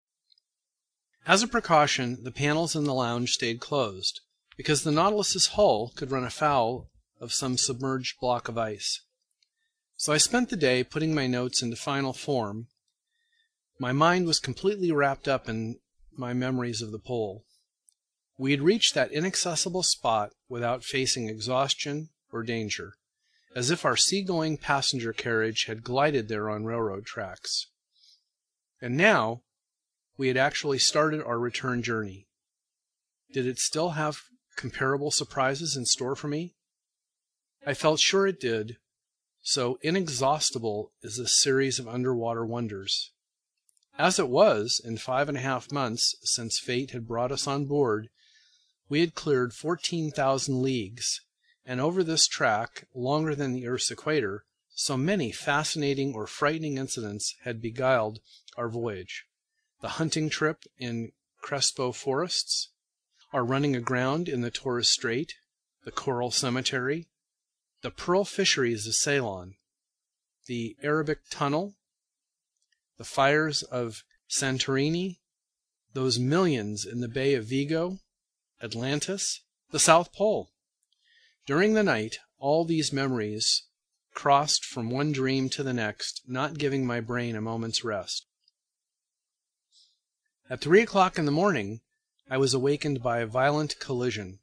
英语听书《海底两万里》第460期 第28章 惊奇还是意外(2) 听力文件下载—在线英语听力室
在线英语听力室英语听书《海底两万里》第460期 第28章 惊奇还是意外(2)的听力文件下载,《海底两万里》中英双语有声读物附MP3下载